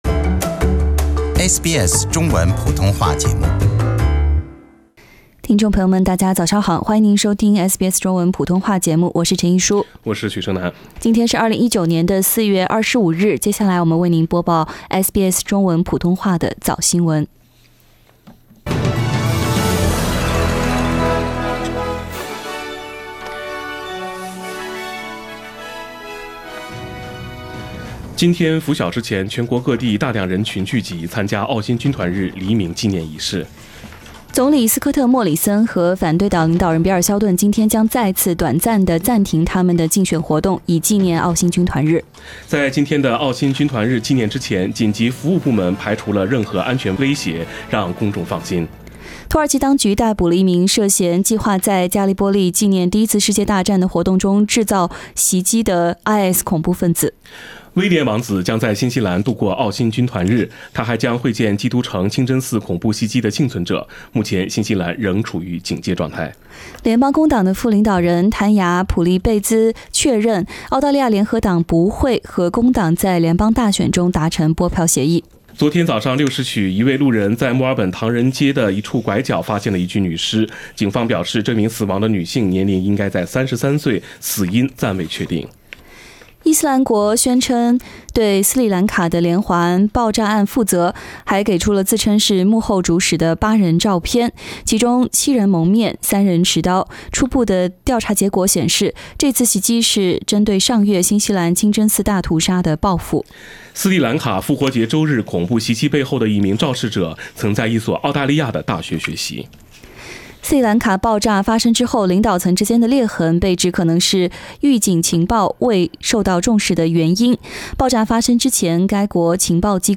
SBS早新闻 （4月25日）